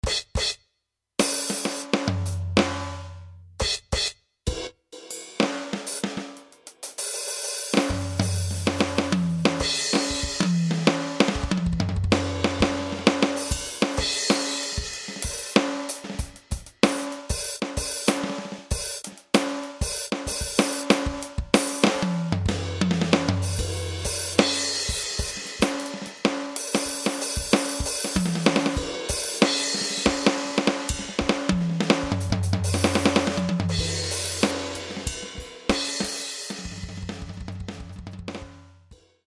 V-DRUMシリーズの廉価版ということで音色変化は細かくないけど練習用としては十分です。ゴムパッドも思ったより静かだった。
結構コンプが強めで（セッティングできるのかな？）、アンサンブル向けの
音質。400Hz辺り削ればそのまんまライブだとかデモテープだとかに使えそうなのでお手軽ですな。